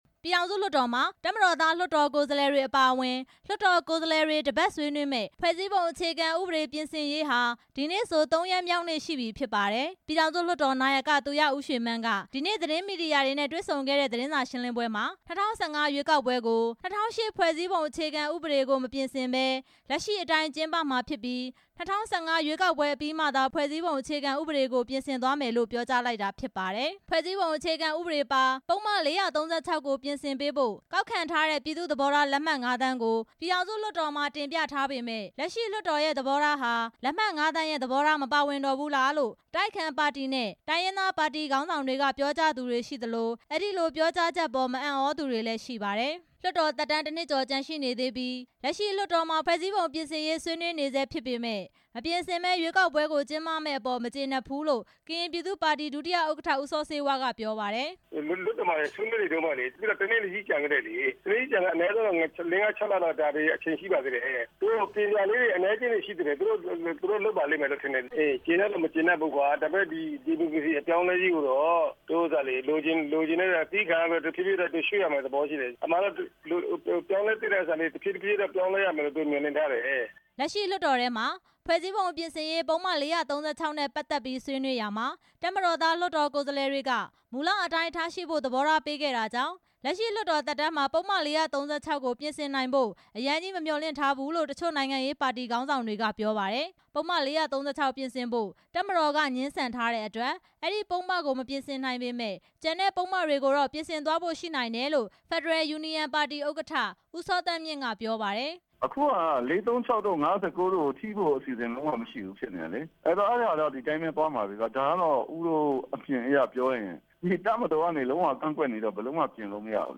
နိုင်ငံရေးပါတီ ခေါင်းဆောင်တွေကို မေးမြန်းချက် နားထောင်ရန်